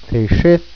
Tth-ey sh-eh-th